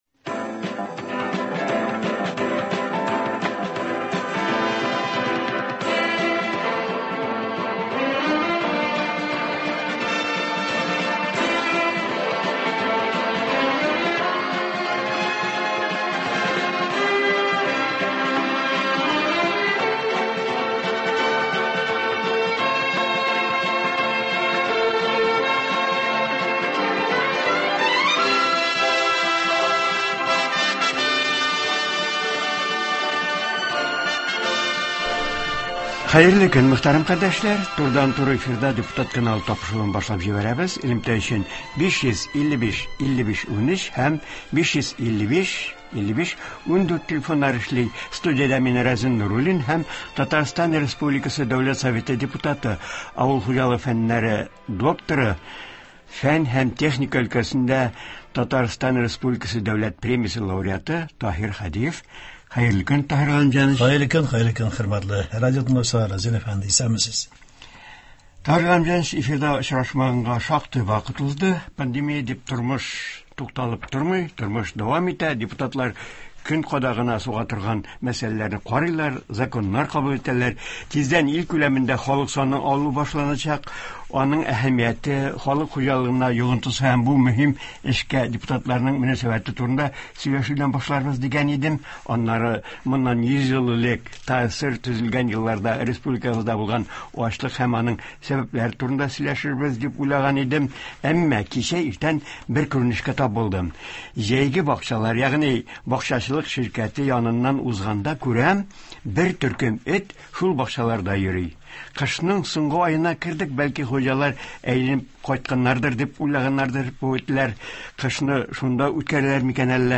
Тарих кабатлану куркынычы юкмы? Татарстан республикасы Дәүләт Советы депутаты, авыл хуҗалыгы фәннәре докторы Таһир Һадиев турыдан-туры эфирда шул хакта сөйли, тыңлаучыларны кызыксындырган сорауларга җавап бирә.